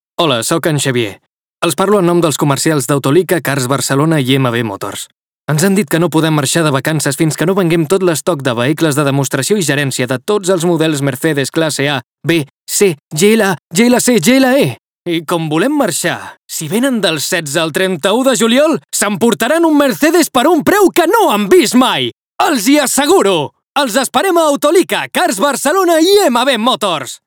Catalán: Castellano: Participación en la campaña publicitaria como Voz en Off.
TIPO: Campaña publicitaria – Radio.